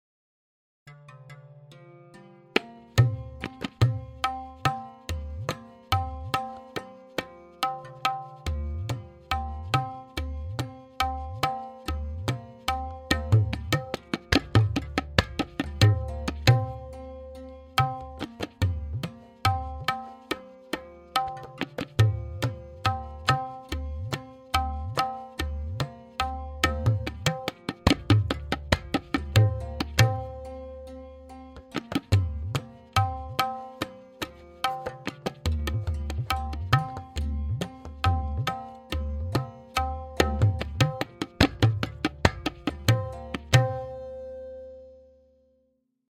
Mukhra Played 3 Times with Theka and Lahra
M8.5-Mukhra-3x-Theka-Lehra-V2.mp3